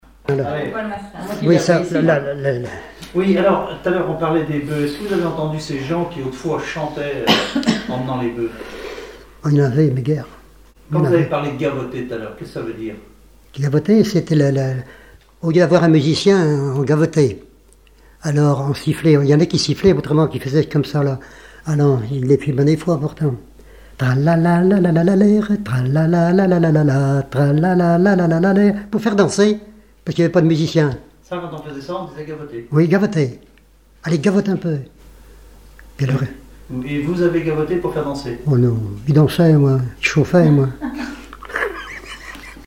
chanteur(s), chant, chanson, chansonnette
regroupement de chanteurs locaux
Catégorie Témoignage